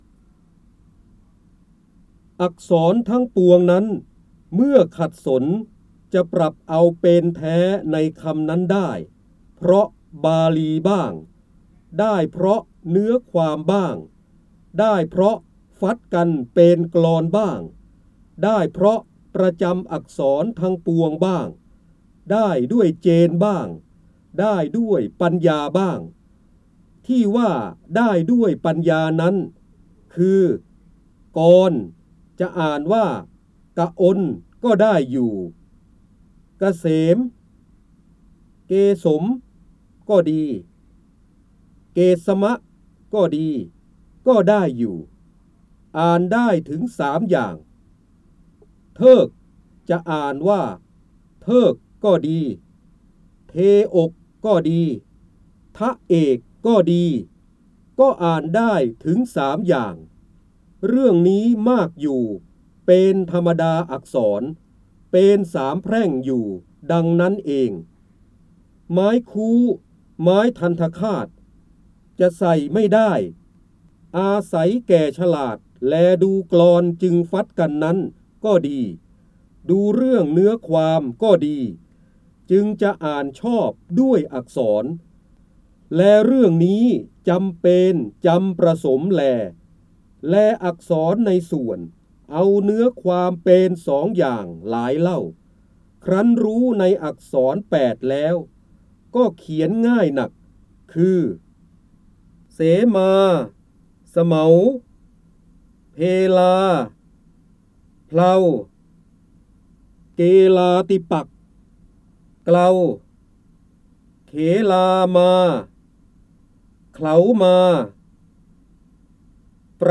เสียงบรรยายจากหนังสือ จินดามณี (พระเจ้าบรมโกศ) อักษรทั้งปวงนั้น เมื่อ..
คำสำคัญ : ร้อยแก้ว, พระเจ้าบรมโกศ, ร้อยกรอง, จินดามณี, พระโหราธิบดี, การอ่านออกเสียง